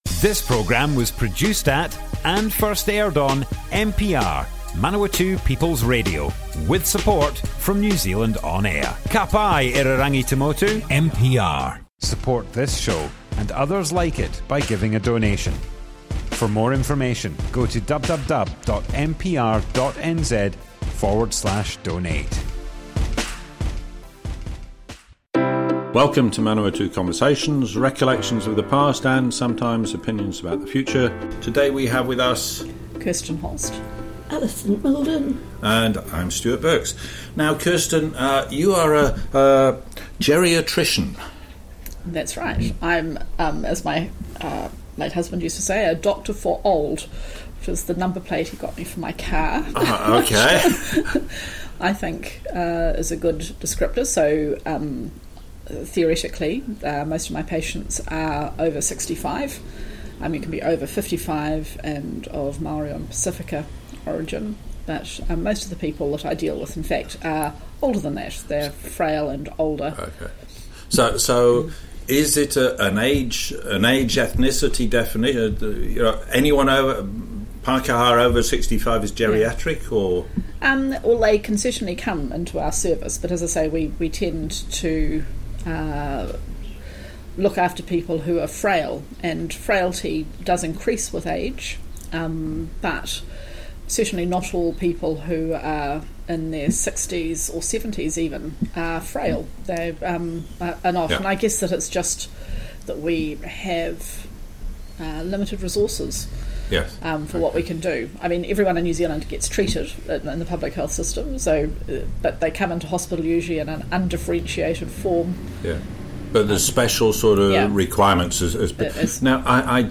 Manawatu Conversations More Info → Description Broadcast on 25th January 2022. part 1 of 2. Geriatrician, looking after frail people, mix of conditions, so more holistic, teamwork.
oral history